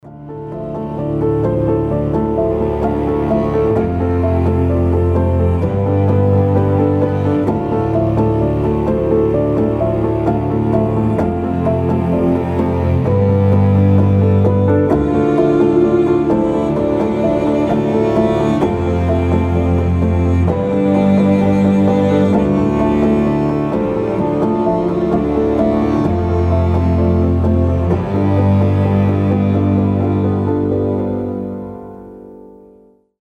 • Качество: 320, Stereo
мелодичные
indie pop
Folk Rock
волшебные
красивый женский вокал
alternative
indie folk